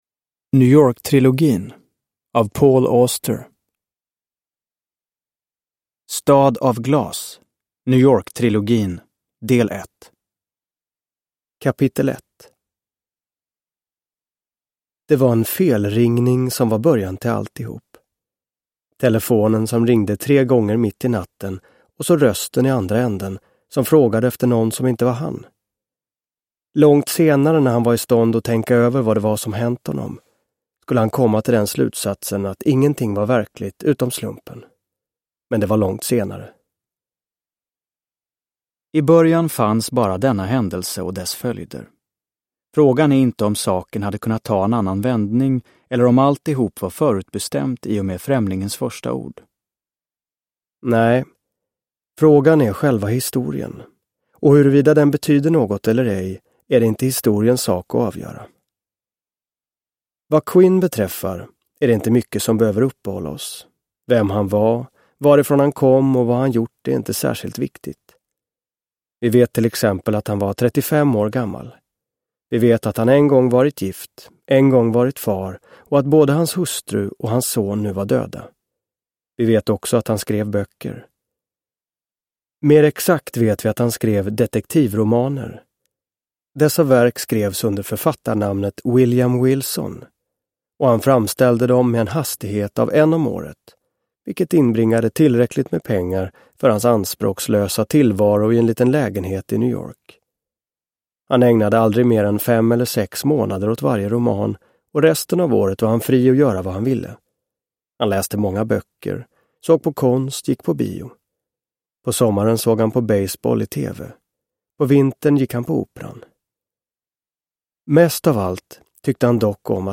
New York-trilogin – Ljudbok – Laddas ner
Uppläsare: Martin Wallström